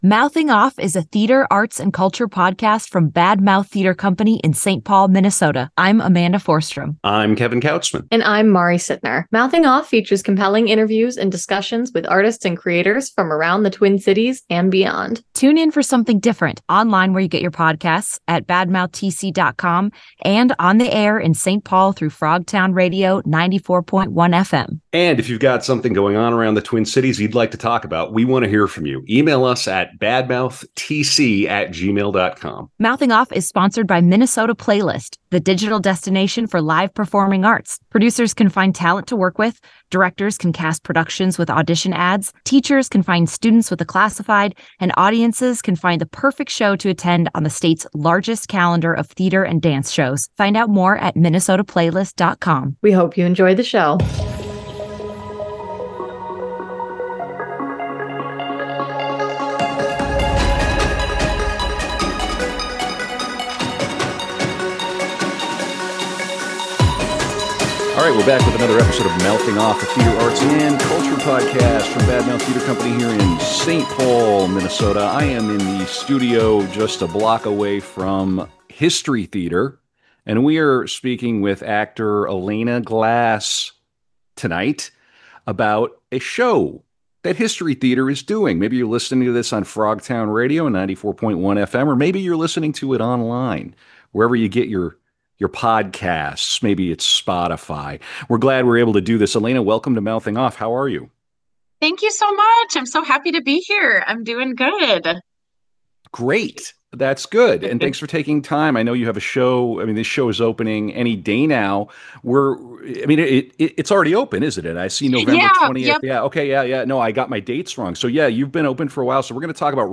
The show features compelling interviews and discussions with artists and creators from around the Twin Cities and beyond.